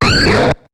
Cri de Carmache dans Pokémon HOME.